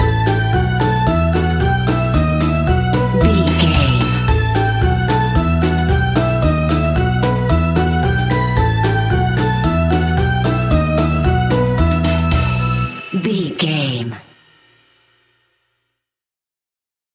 A great piece of royalty free music
Aeolian/Minor
SEAMLESS LOOPING?
DOES THIS CLIP CONTAINS LYRICS OR HUMAN VOICE?
fun
childlike
cute
happy
kids piano